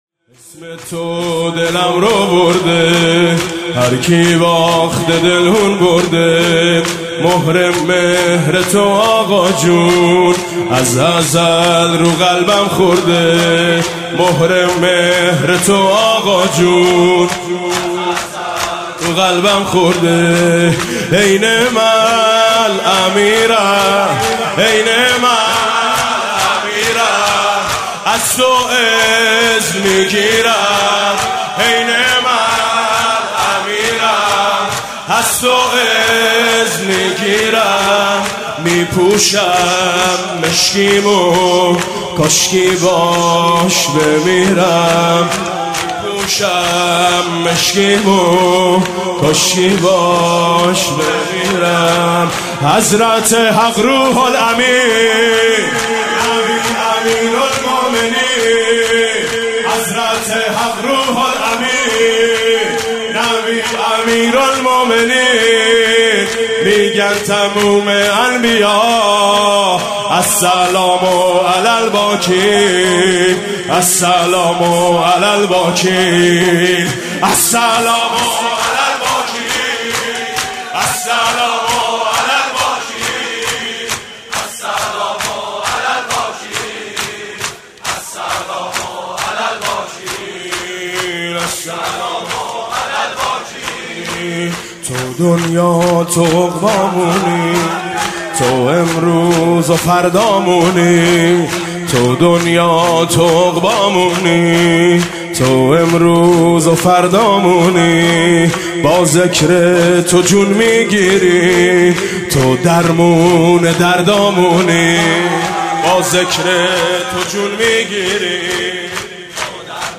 خیمه گاه - حاج میثم مطیعی - 23مهر98-عمود 932 - شور -انگار میخونه مادری، السلام علی الباکین
حاج میثم مطیعی
اربعین 98